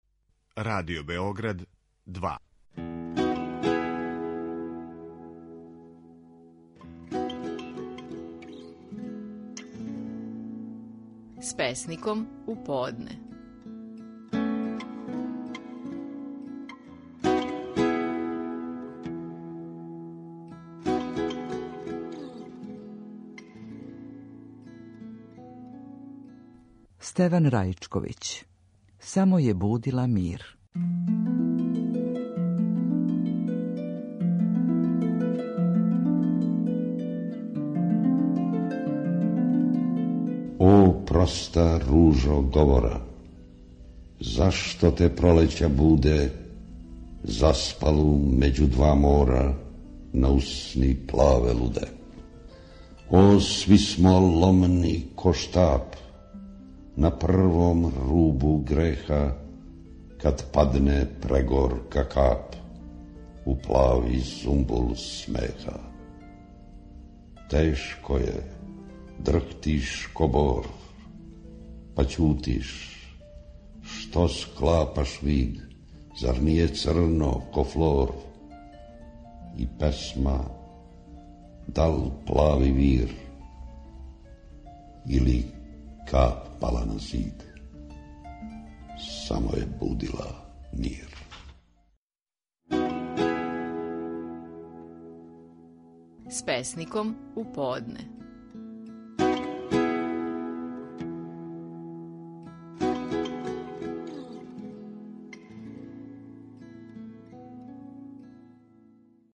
Стихови наших најпознатијих песника, у интерпретацији аутора.
Стеван Раичковић говори песму: „Само је будила мир".